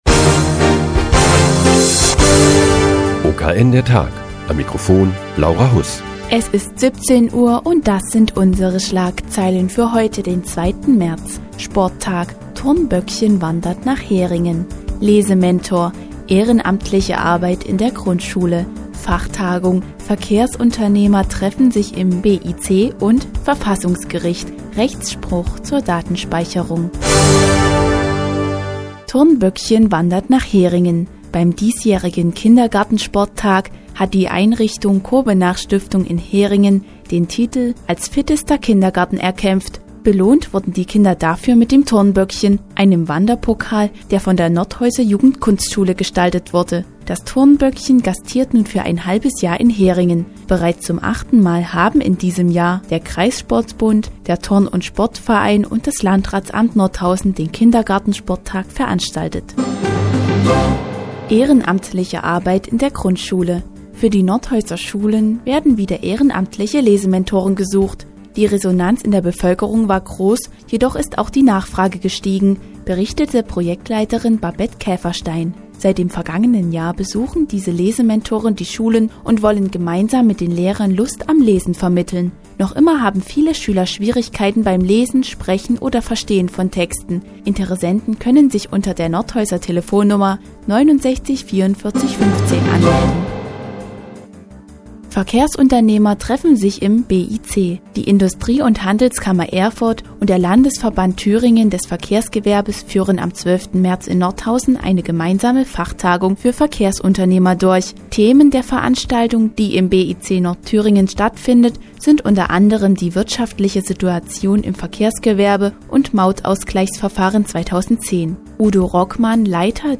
Die tägliche Nachrichtensendung des OKN ist nun auch in der nnz zu hören. Heute geht es um den diesjährigen Kindersporttag und eine Fachtagung für Verkehrsunternehmer im BIC Nordthüringen.